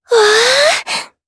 Lorraine-Vox_Happy4_jp.wav